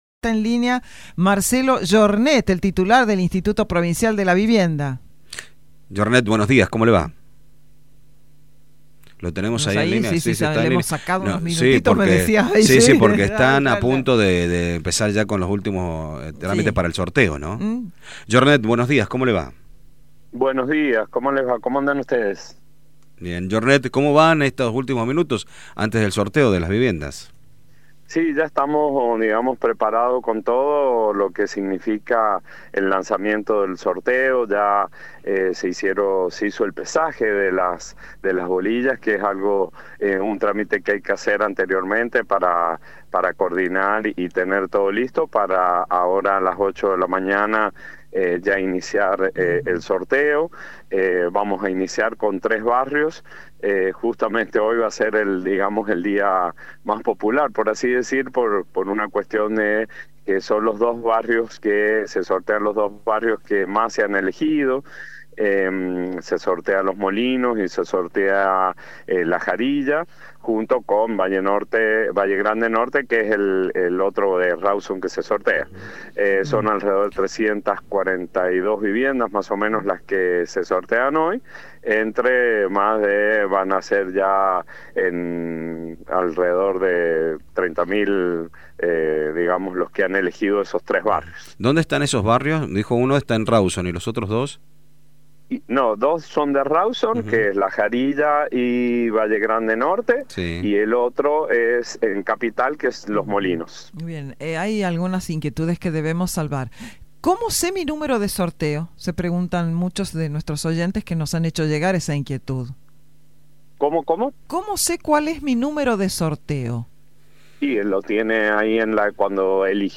Esta mañana, el titular del IPV Marcelo Yornet charló con LV5 Sarmiento momentos previos al comienzo del primer día de sorteo de casas de la Institución. Comentó los preparativos que se realizaron por Caja de Acción Social y aclaró que el número designado para los participantes es el elegido por la web y el que aparece en los padrones.